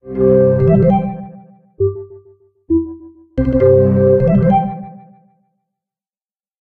incoming-call.oga